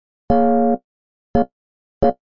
描述：电钢琴100bpm的Bbm节奏和弦
Tag: 和弦 钢琴